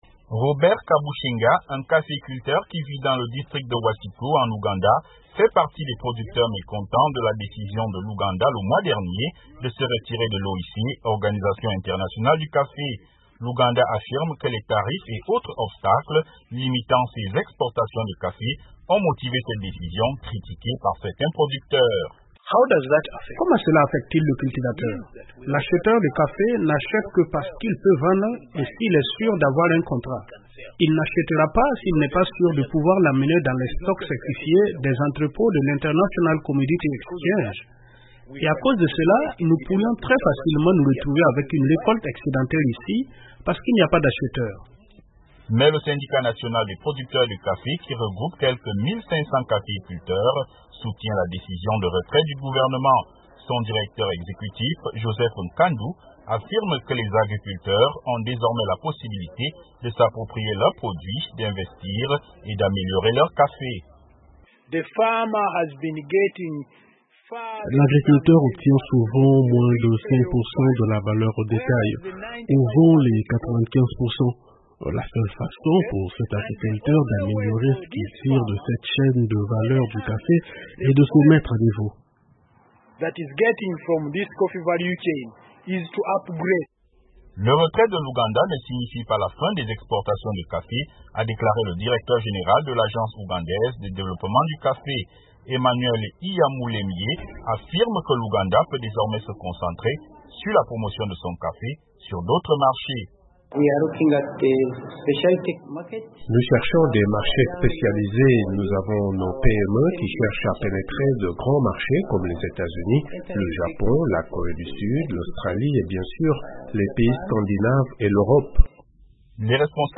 Un reportage à Kampala